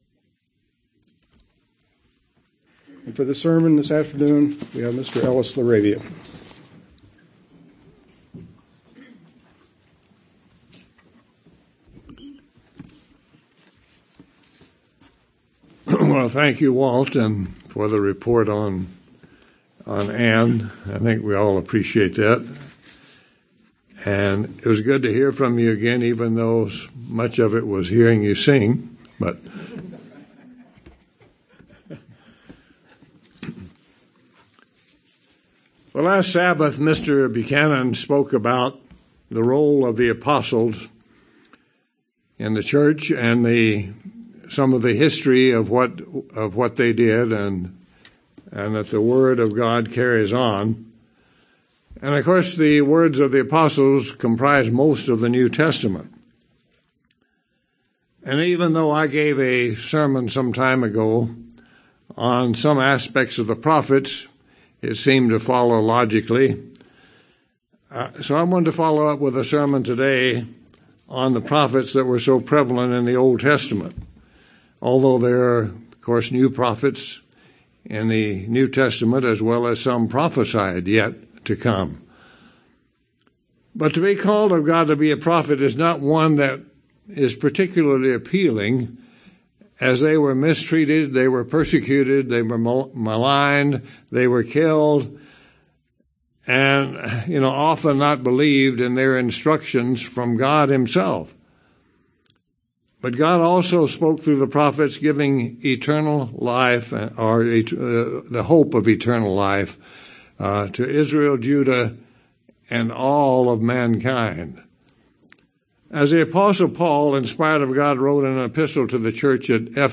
While God's prophets suffered abundant persecution and even death at the hands of men, God spoke through them vital prophecies concerning the first and second coming of Christ, as well as the Kingdom of God, the New Covenant, and instructions in obedience. This sermon is a brief insight into some of the prophets and prophecies of the past as well as the future.
Given in Tucson, AZ El Paso, TX